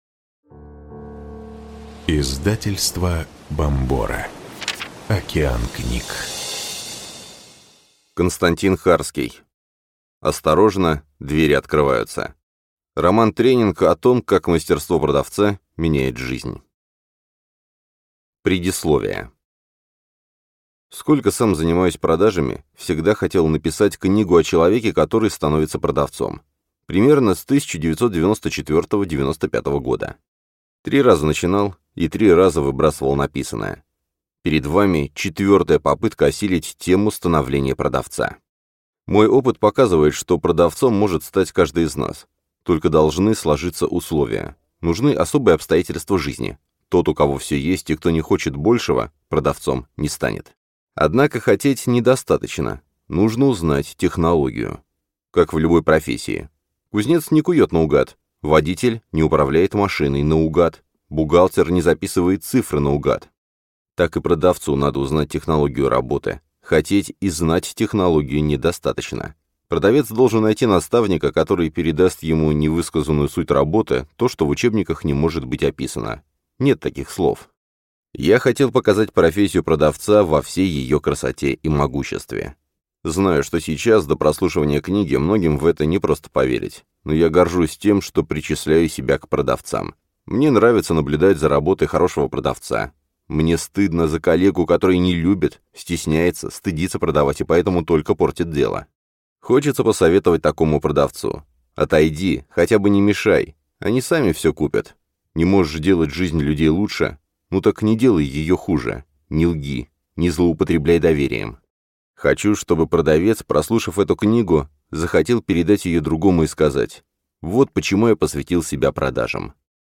Аудиокнига Осторожно, двери открываются. Роман-тренинг о том, как мастерство продавца меняет жизнь | Библиотека аудиокниг